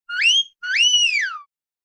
Download Free Cartoon Whistle Sound Effects | Gfx Sounds
Cartoon-wolf-whistle-sexy-whistle.mp3